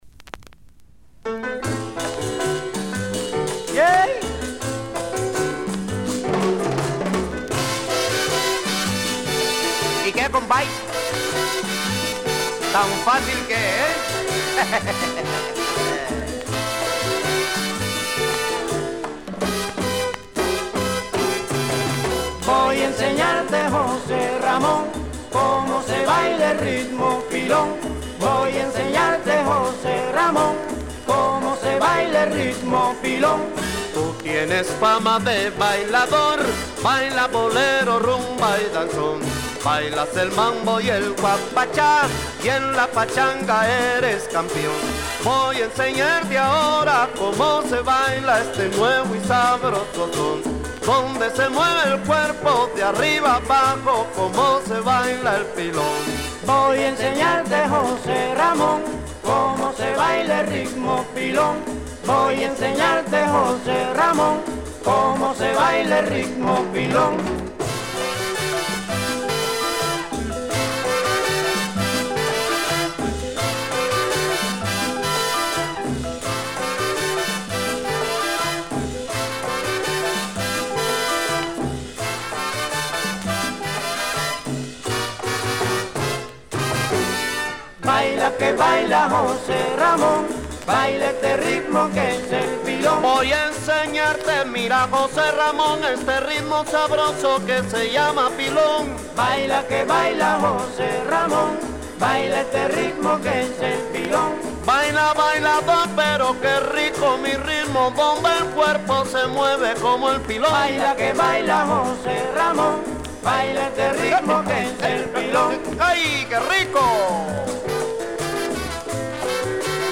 Cuban Jazz , Mambo , Guaracha , Son Montuno !!!